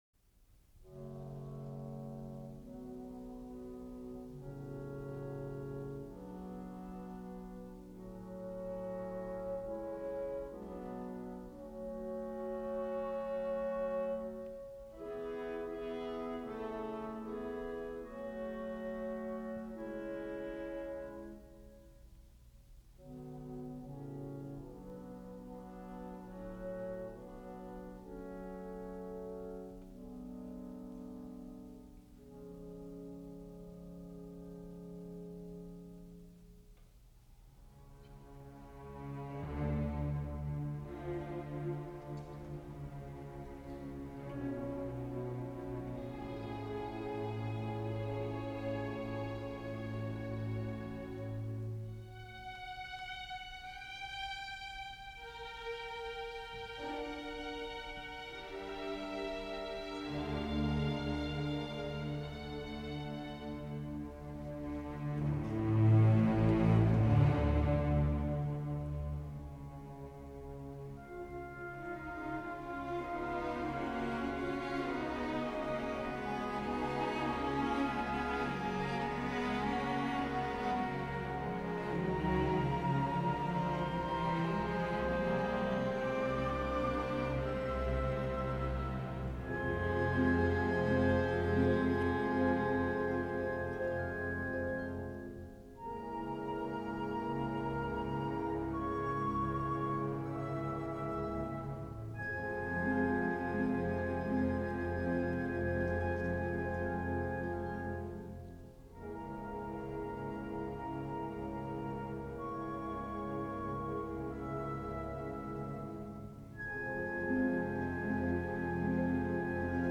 Romeo and Juliet is an example of program music, music that tells a story or describes a particular scene. In this instance, characters and incidents from the play are presented as different musical themes, structured in sonata form.
03-romeo-and-juliet_-overture-fantas.mp3